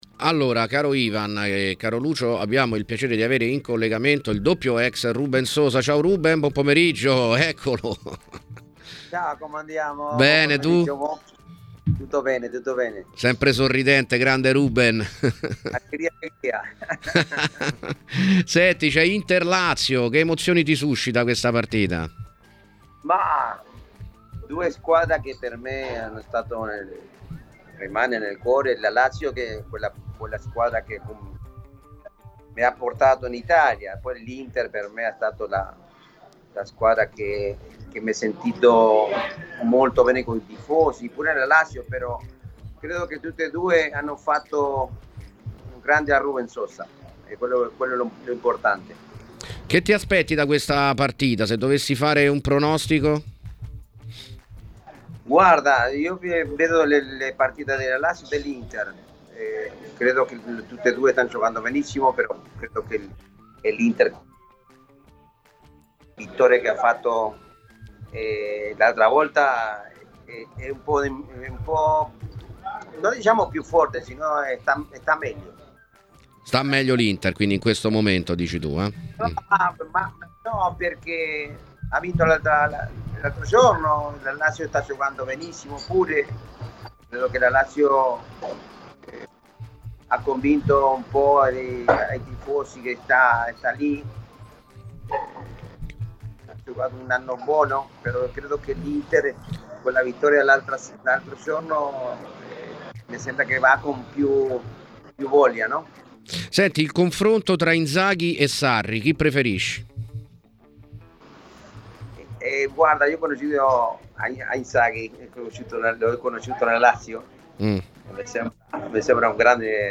L’ex nerazzurro e biancoceleste Rubén Sosa ha parlato ai microfoni di TMW Radio nel pieno della trasmissione 'Piazza Affari' per commentare l'imminente match tra Inter e Lazio: “Sono due squadre che mi sono rimaste nel cuore.